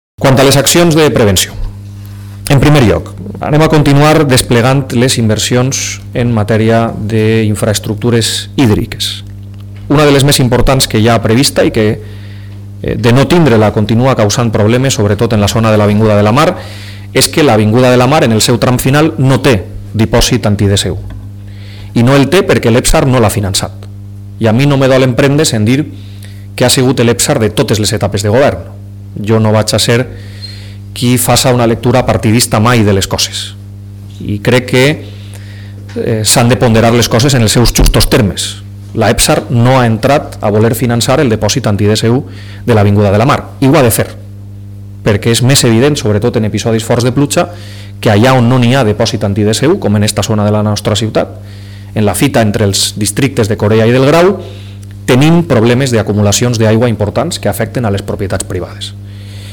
El alcalde de Gandia, José Manuel Prieto, acompañado de la primera tenienta de alcalde y edila de Medio Ambiente, Alicia Izquierdo; el concejal de Gobierno Interior, Adrià Vila; la concejala de Protección, Seguridad y Convivencia, Lydia Morant; y del Director General de Calidad Urbana y Servicios Básicos, Sebas Gálvez, ha comparecido hoy ante los medios para informar sobre las medidas que el Ayuntamiento va a poner en marcha tras los últimos episodios de lluvias torrenciales y sobre las acciones relacionadas con la prevención de emergencias.
alcalde Prieto (audio).